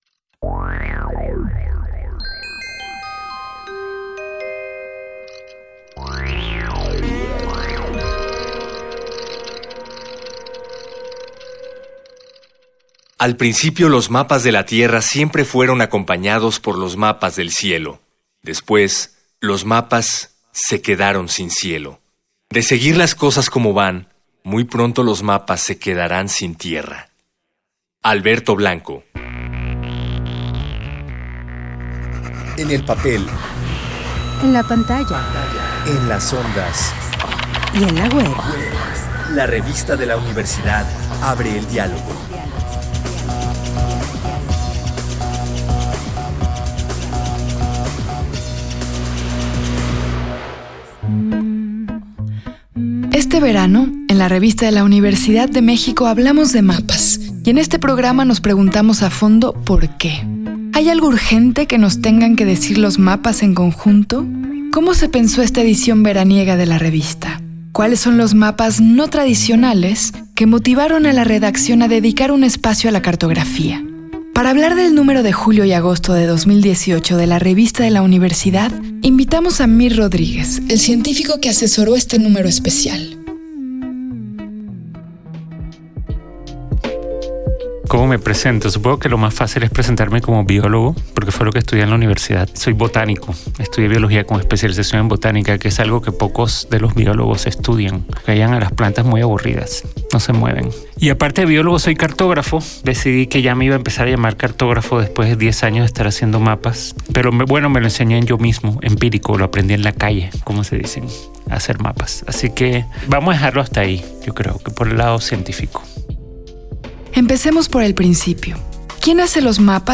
Fue transmitido el jueves 30 de agosto de 2018 por el 96.1 FM.